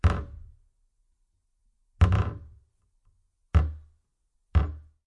随机的 " 门上的木柜颠簸的吱吱声
描述：门木柜颠簸creaks.flac
Tag: 碰伤 吱吱作响